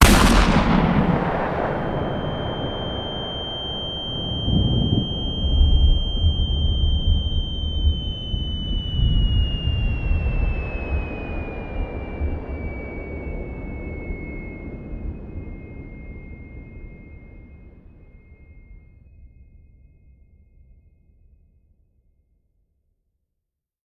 shotTinnitus.ogg